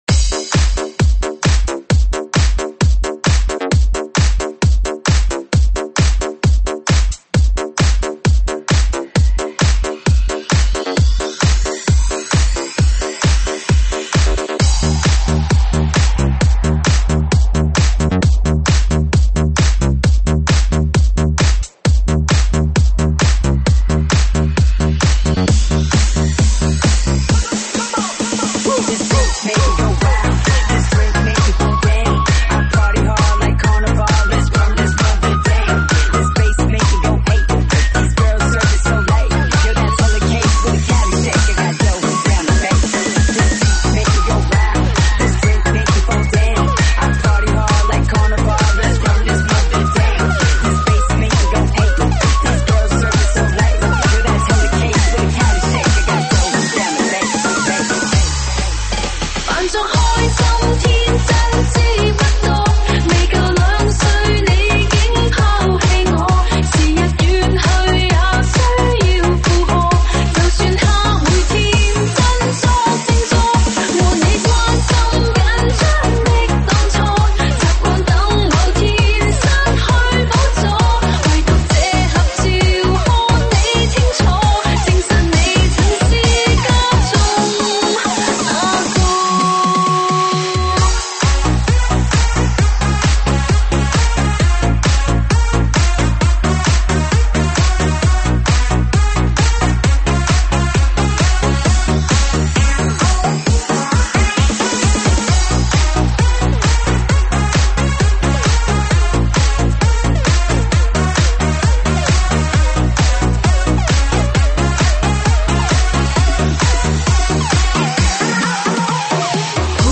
中文舞曲
舞曲类别：中文舞曲